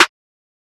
{Snare} Easybake.wav